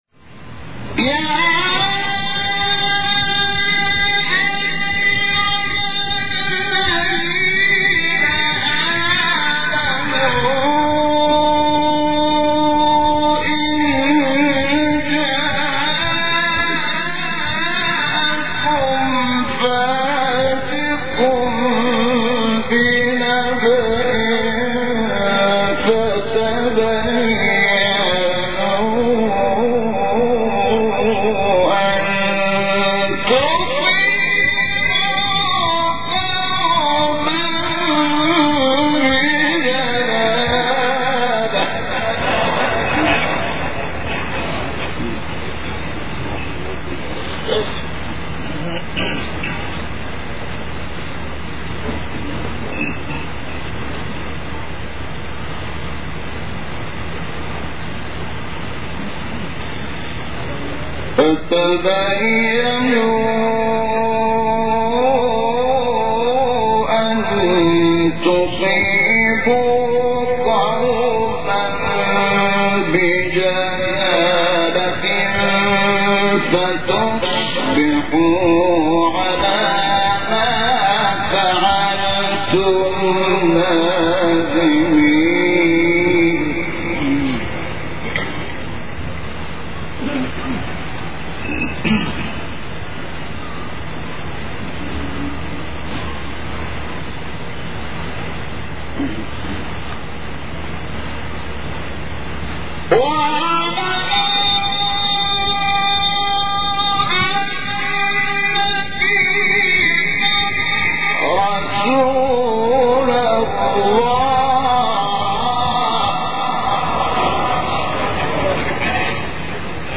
آیه 6-9 سوره حجرات استاد شحات انور | نغمات قرآن | دانلود تلاوت قرآن